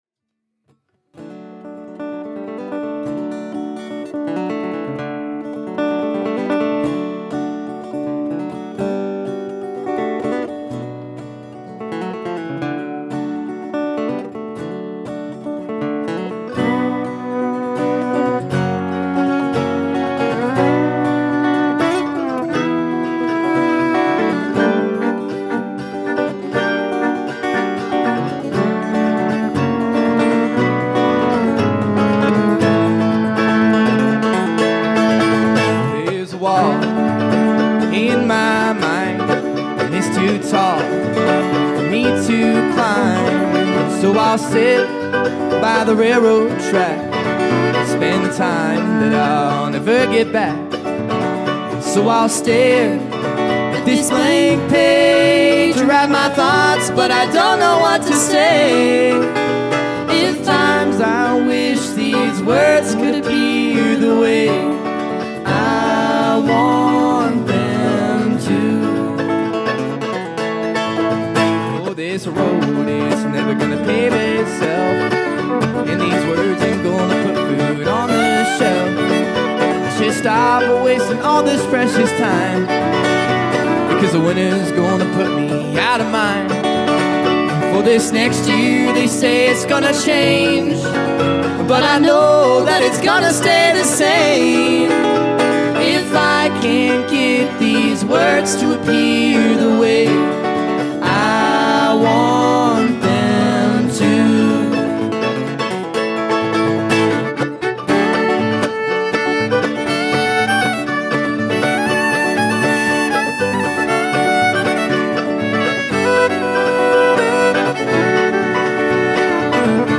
Sierra Pines Resort Sierra City, CA
Guitar, Octave Mandolin
Mandolin, Guitar
Fiddle